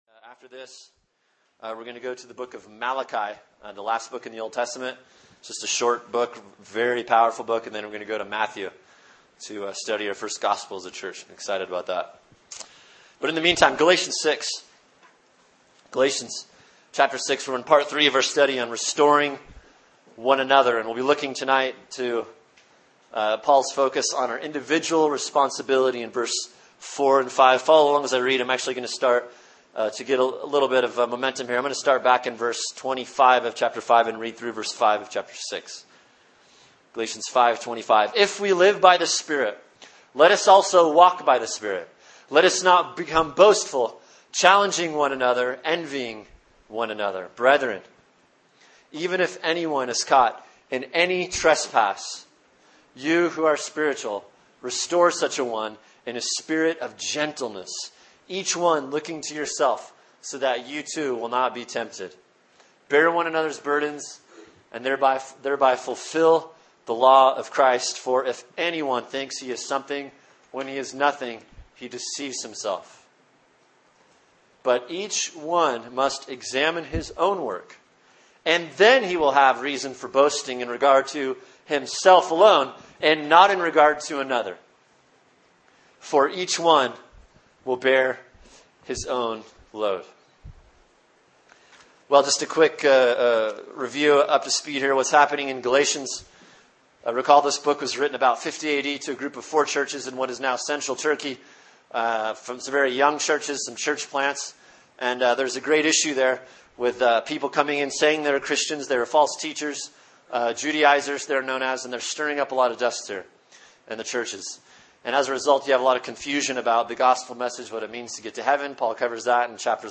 Sermon: Galatians 6:4-5 “Restoring One Another” | Cornerstone Church - Jackson Hole